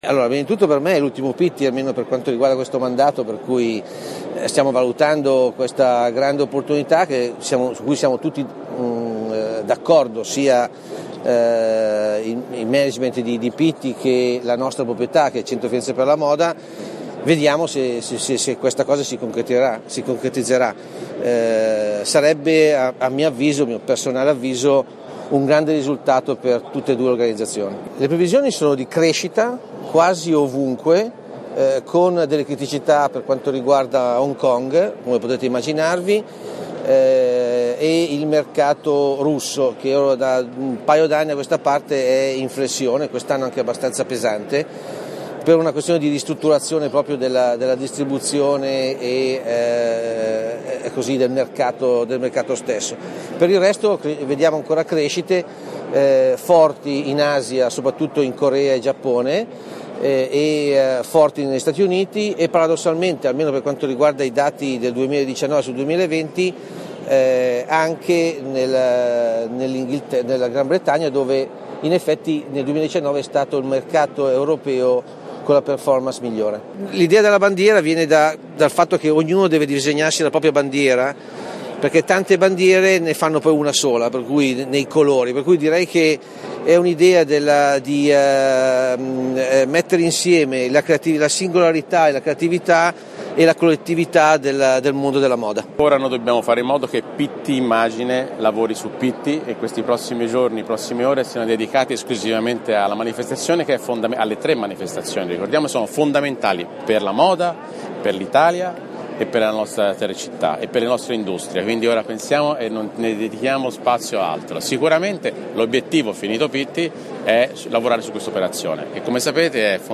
Speciale audio con interviste